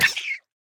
Minecraft Version Minecraft Version 1.21.5 Latest Release | Latest Snapshot 1.21.5 / assets / minecraft / sounds / mob / axolotl / death1.ogg Compare With Compare With Latest Release | Latest Snapshot
death1.ogg